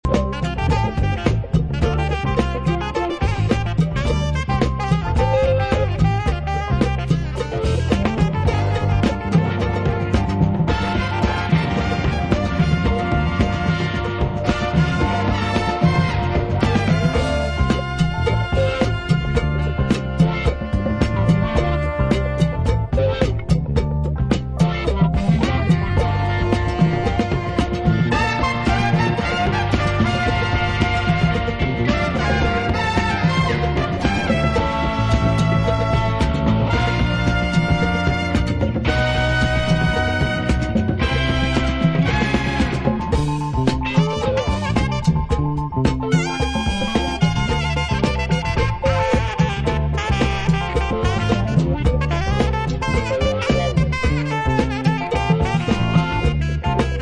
Afrobeat , Funk , Funk / soul